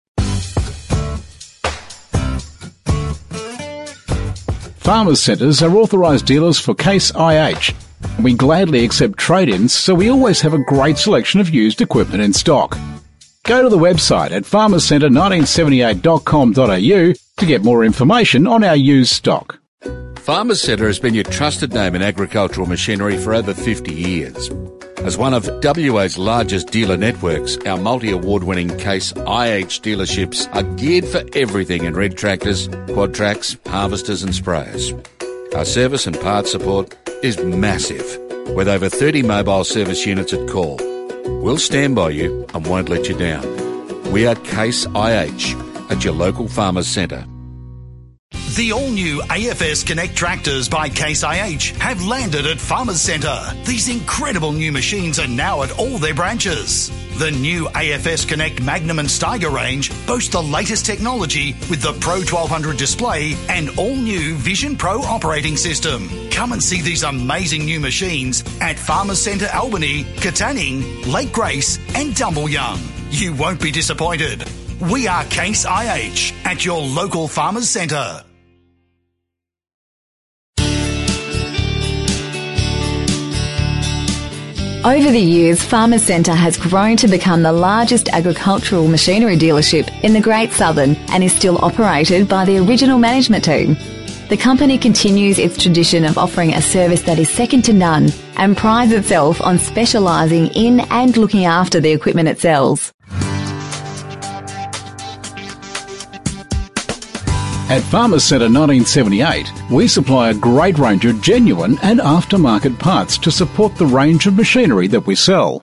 A better customer experience – On Hold